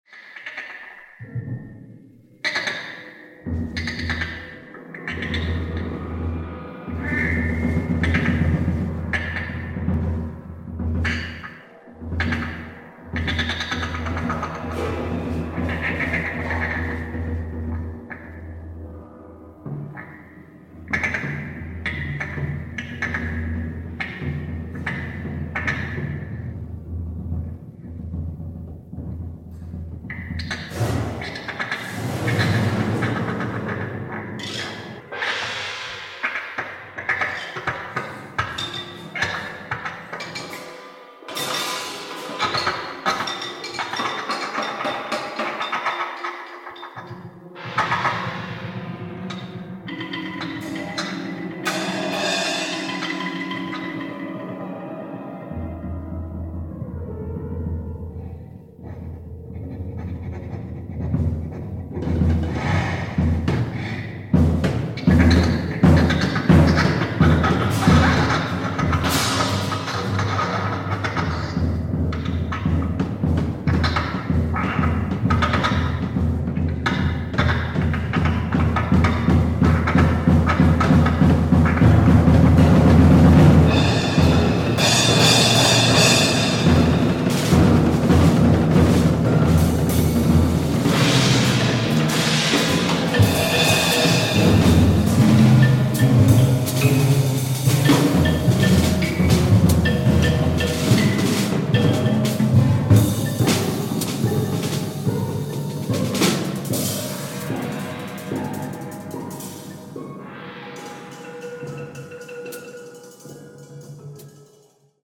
French experimental jazz
Remastered from the master tapes.
organ
drums
tuba
behind his electric piano